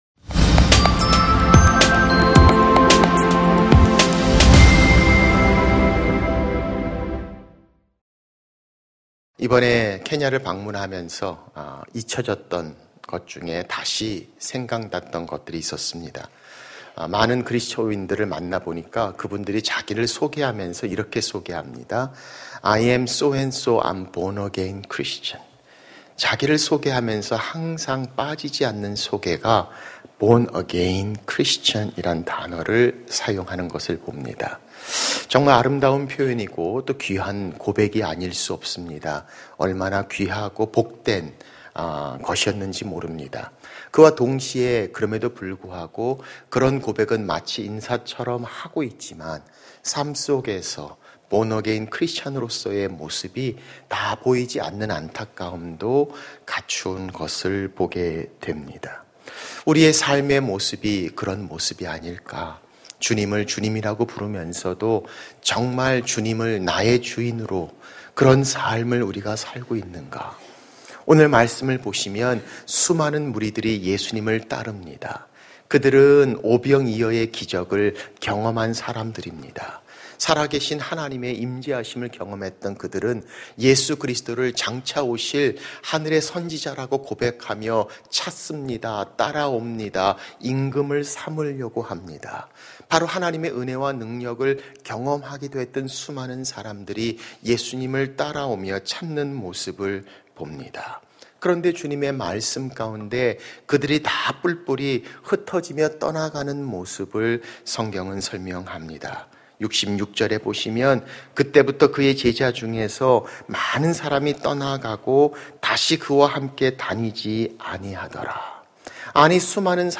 설교 | (1) 나의 주!